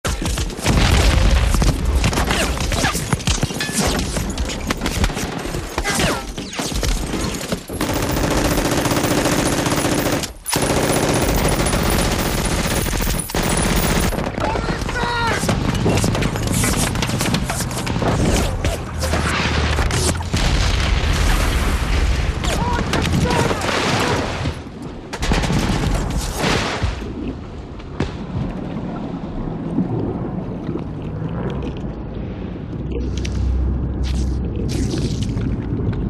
war_sound_effects
Tags: Sci Fi Play